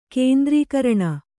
♪ kēndrīkaraṇa